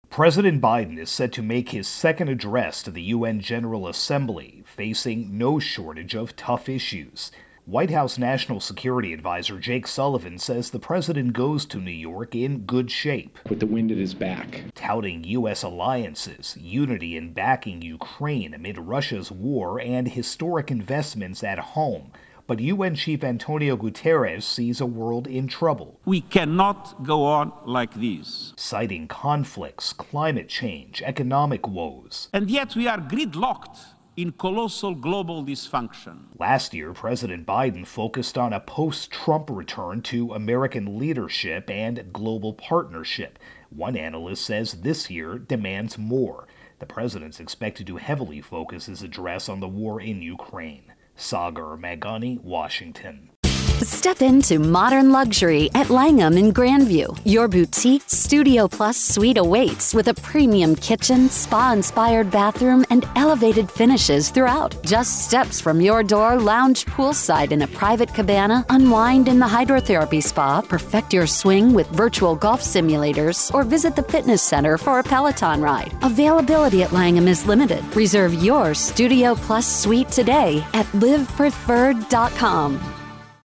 reports on UN-General Assembly-Biden.